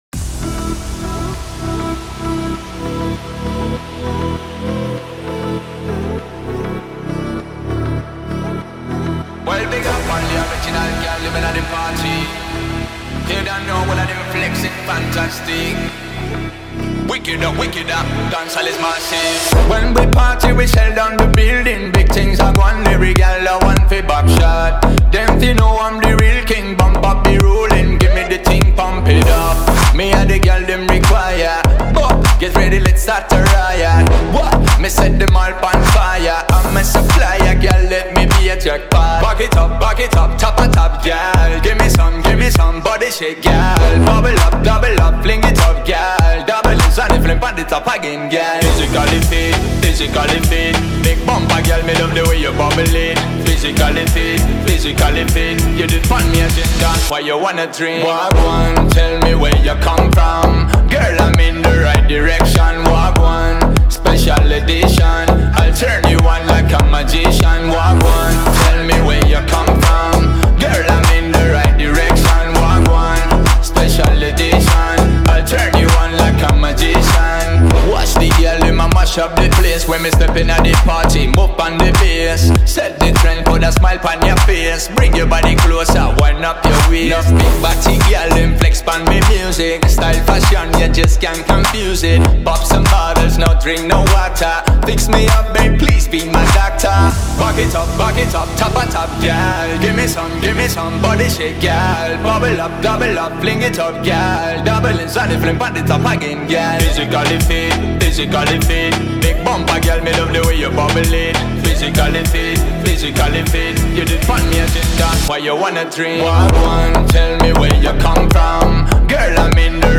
это зажигательная танцевальная композиция в жанре EDM